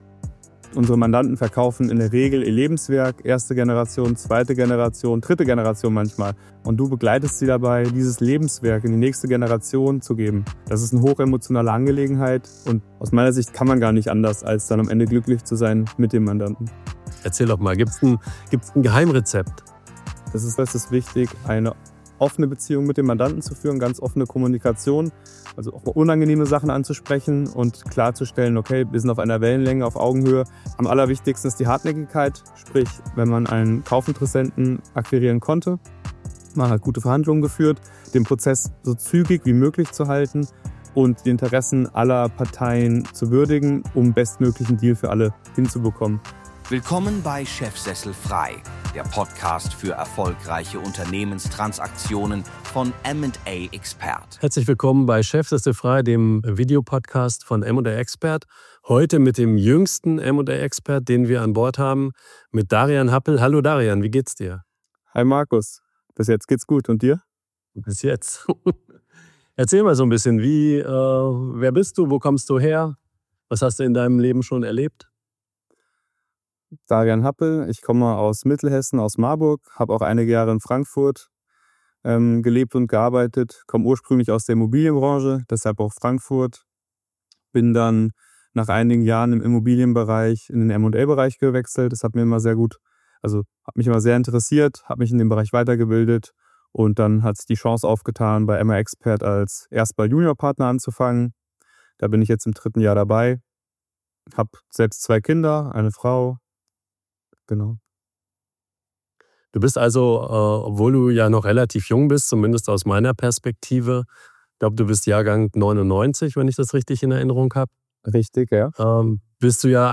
Ein intensives Gespräch über Verantwortung, Vertraulichkeit, Psychologie und Wachstum – im Business wie persönlich.